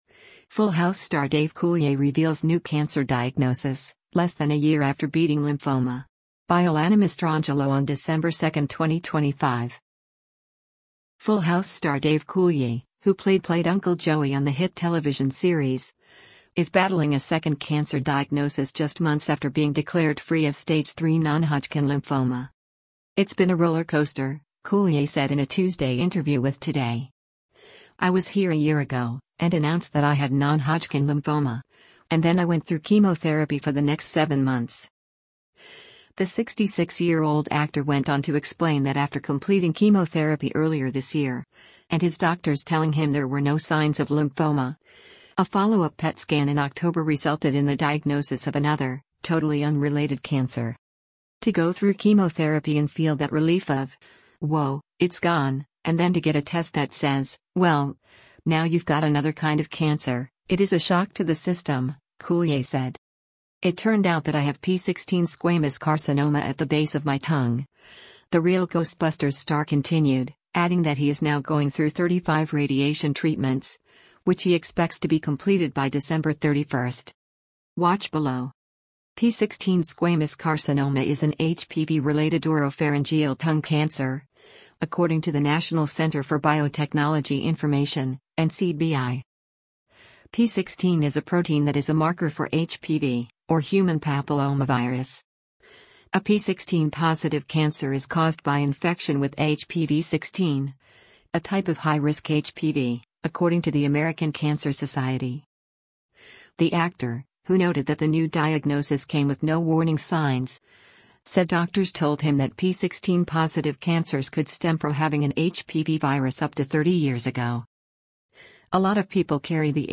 “It’s been a rollercoaster,” Coulier said in a Tuesday interview with Today.